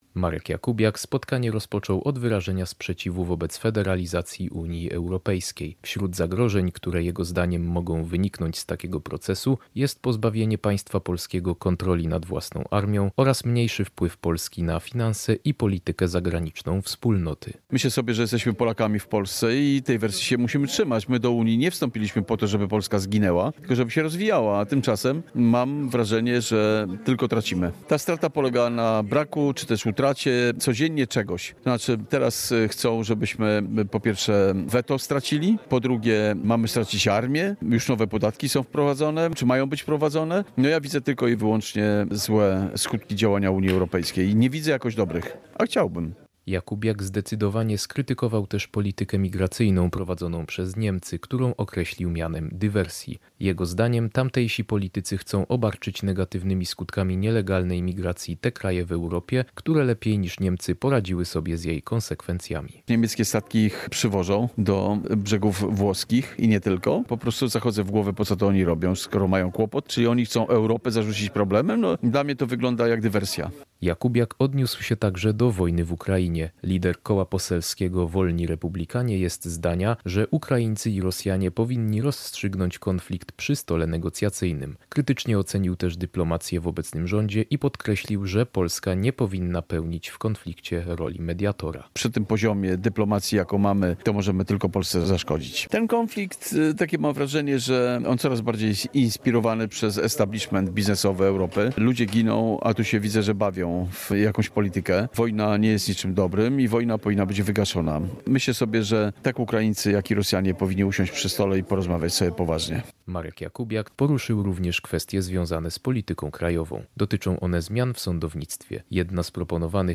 Kilkudziesięciu mieszkańców Nowej Dęby zebrało się dzisiaj w tamtejszym Samorządowym Ośrodku Kultury, by uczestniczyć w otwartym spotkaniu z Markiem Jakubiakiem.